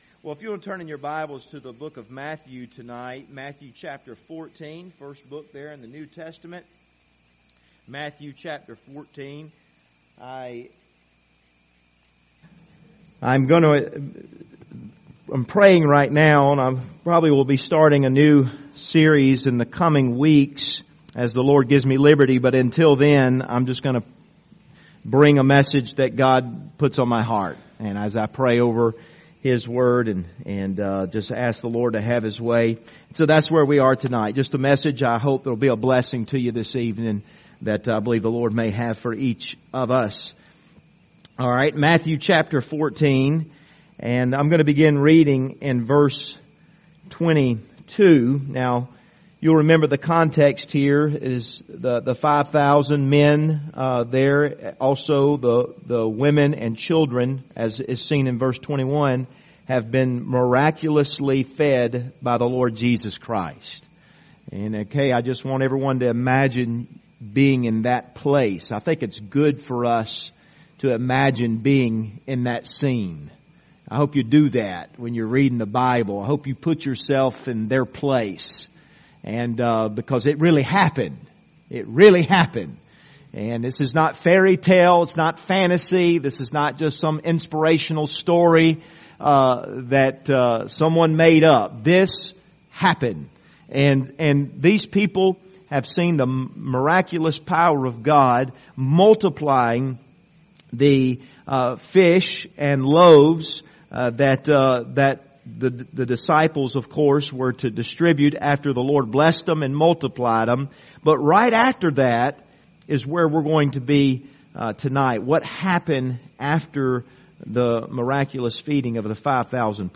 Passage: Matthew 14:22-33 Service Type: Wednesday Evening